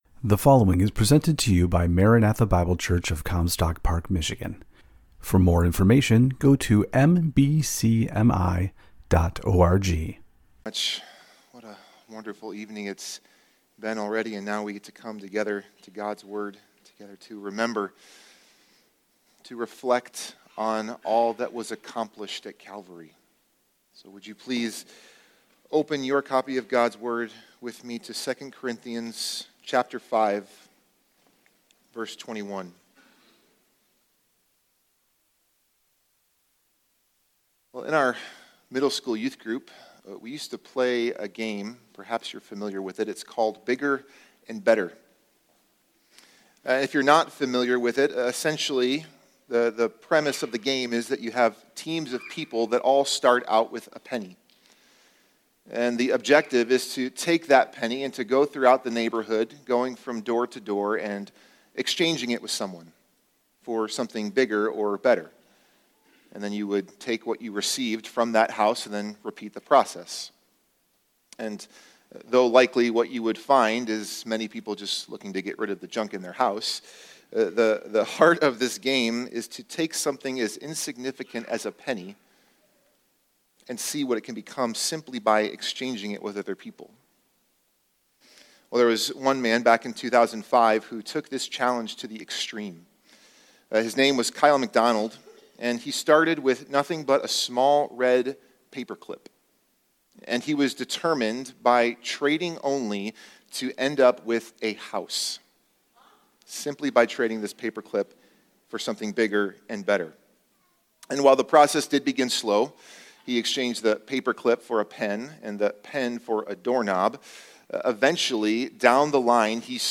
Good Friday